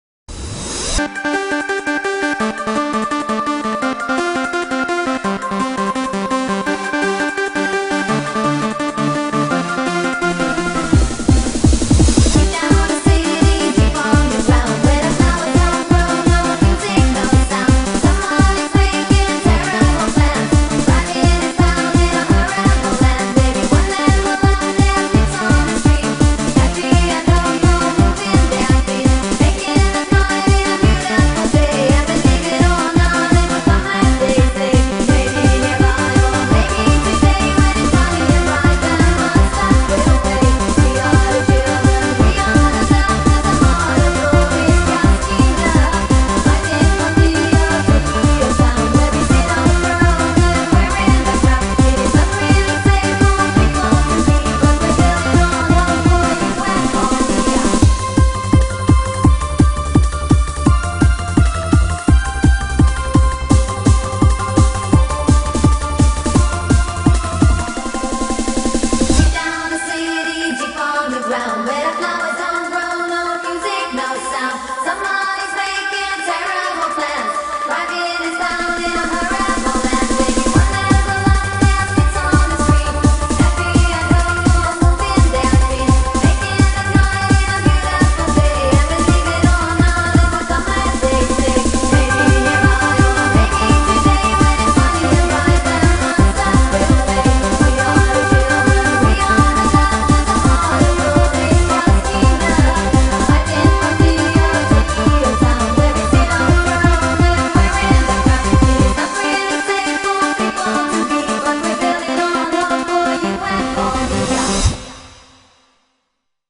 BPM169